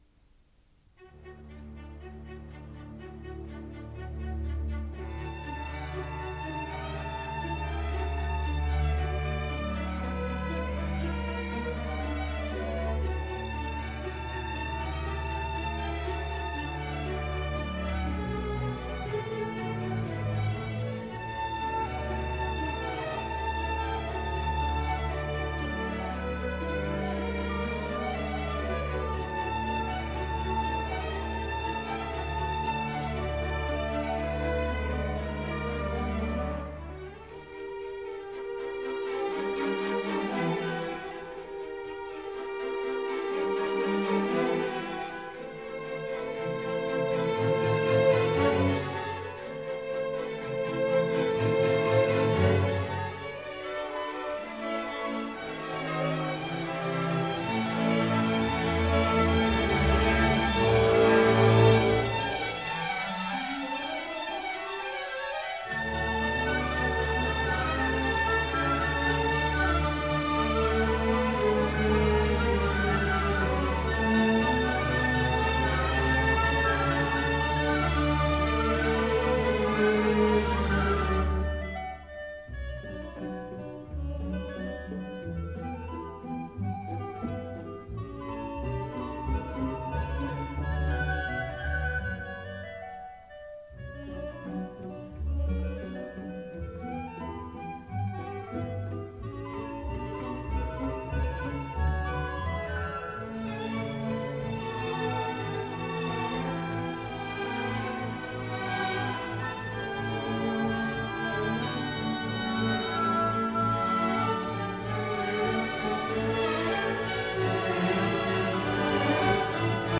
Ballet